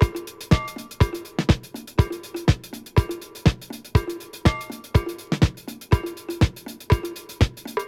• 122 Bpm Fresh Drum Loop Sample G# Key.wav
Free drum loop sample - kick tuned to the G# note. Loudest frequency: 791Hz
122-bpm-fresh-drum-loop-sample-g-sharp-key-2rI.wav